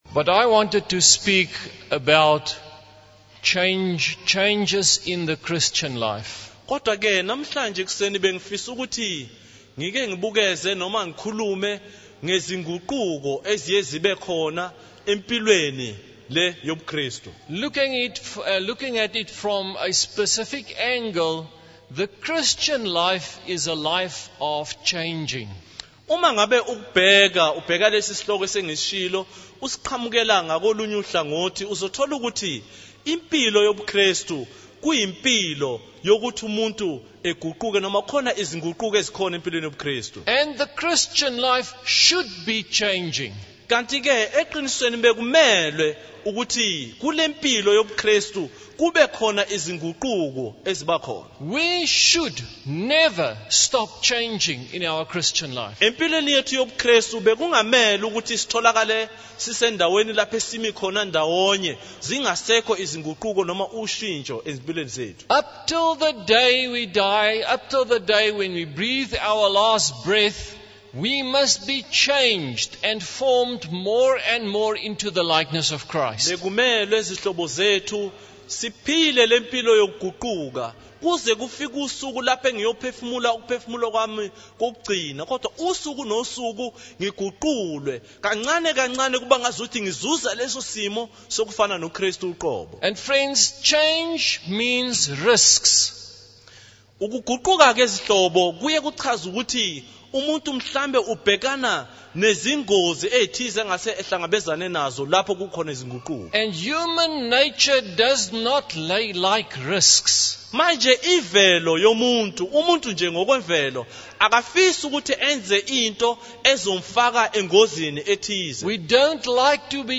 In this sermon, the preacher discusses the concept of change and the challenges that come with it. He uses the example of Israel in the desert after being freed from slavery in Egypt. The preacher emphasizes the importance of obedience and not holding onto the gifts that God gives. He also highlights the dangers of change and the tests that Israel faced during their journey.